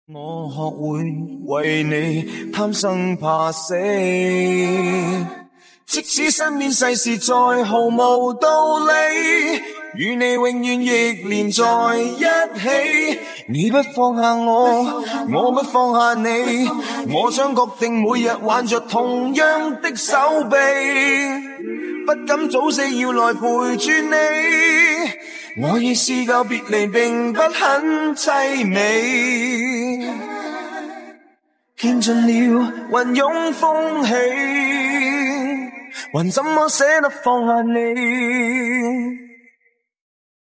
先看效果，男生翻唱
因为使用剪映进行人声提取，所以会有一些和声残留，我这是方便演示，大家不要学我